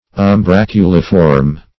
Search Result for " umbraculiform" : The Collaborative International Dictionary of English v.0.48: Umbraculiform \Um*brac`u*li*form\ ([u^]m*br[a^]k"[-u]*l[i^]*f[^o]rm), a. [L. umbraculum any thing that furnishes shade, a bower, umbrella (dim. of umbra a shade) + -form.]
umbraculiform.mp3